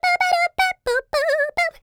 SCAT06.wav